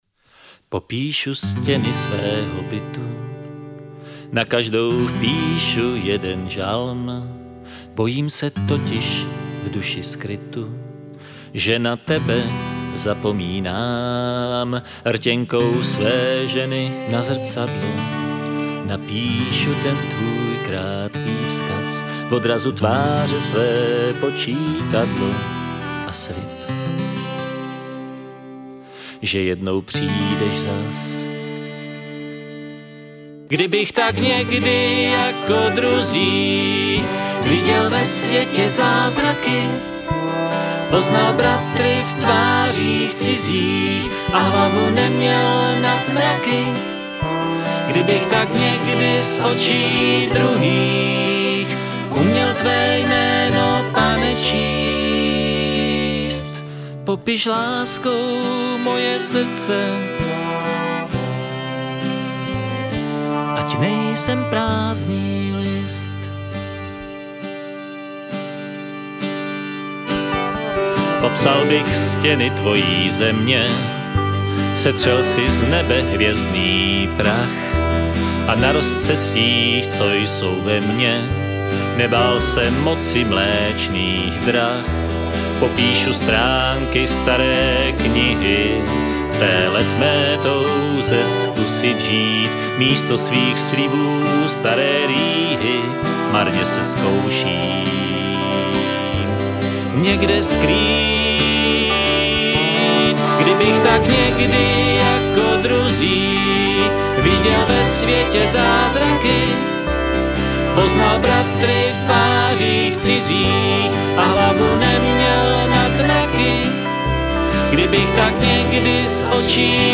Navíc mi sem tam příjde jeho zpěv intonačně nejistý.
basa nádherný zvuk, kreativní a barvitá